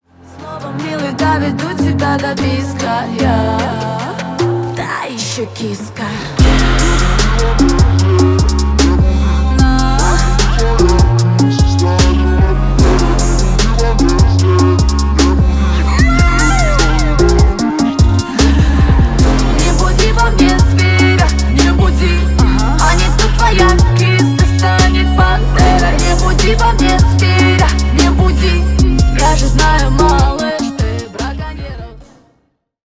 Поп Музыка # восточные